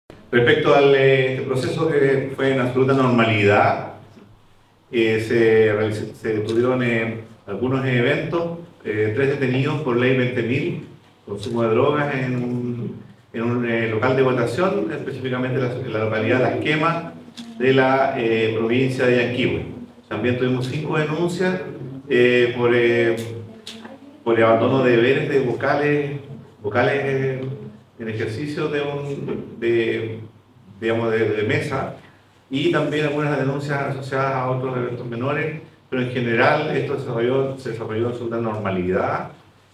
El general Raúl Mera, jefe de las fuerzas para esta contingencia, señaló que el despliegue fue de absoluta normalidad para el personal militar que resguardó los locales de votación.